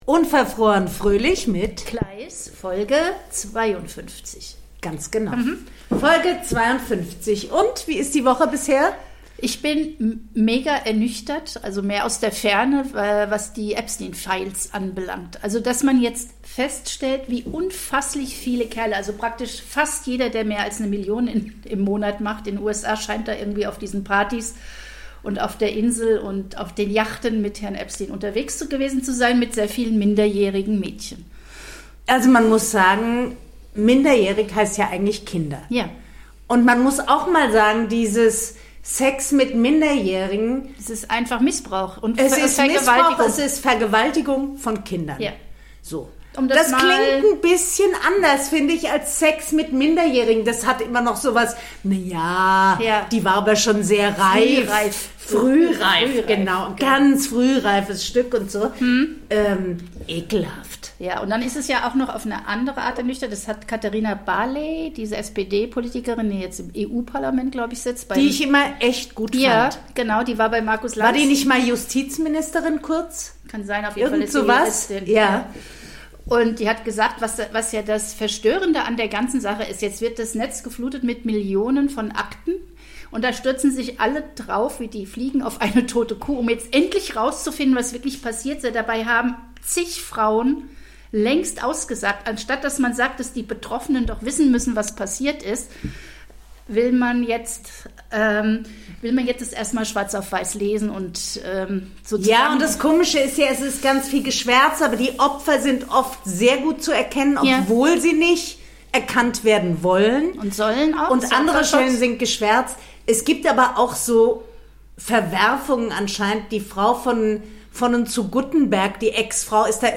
die beiden Podcasterinnen